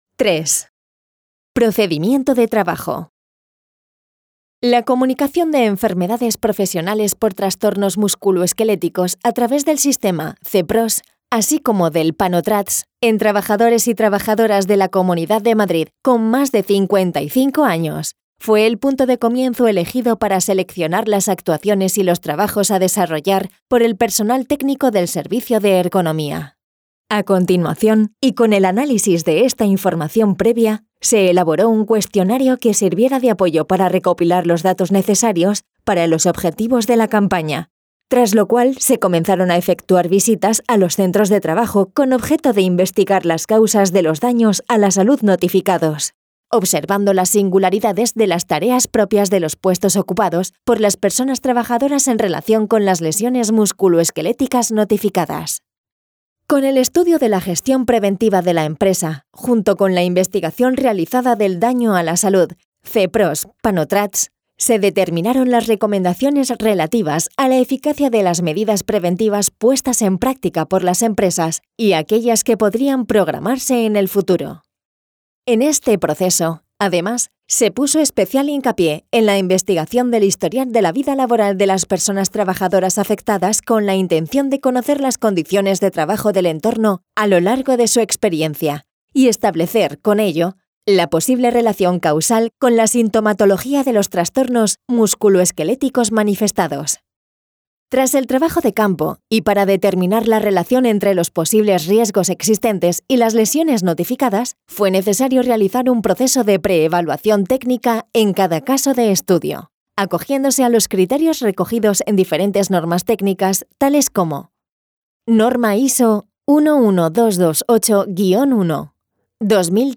Audiolibro 01- Introducción 02- Los trastornos músculo- esqueléticos en miembros superiores 03- Procedimiento de trabajo 04- Presentación de resultados de campaña 05- Conclusiones 06- Mejoras en la gestión de los riesgos